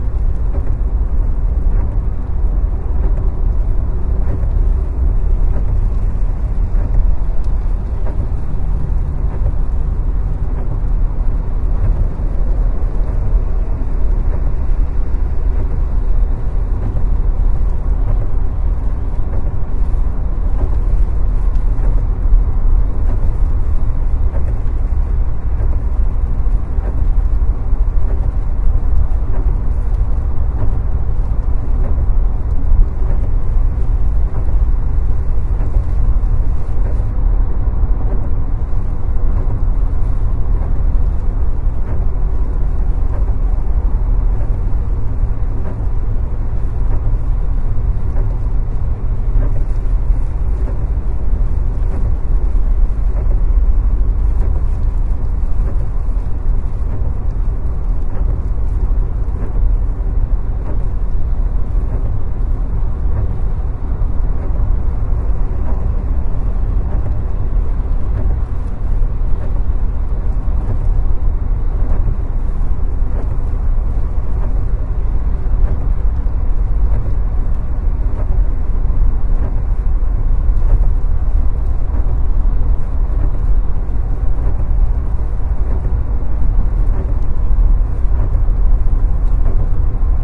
驾车时的雨声 wav
描述：在我的车里时对雨的现场记录。
Tag: 雨车 现场录音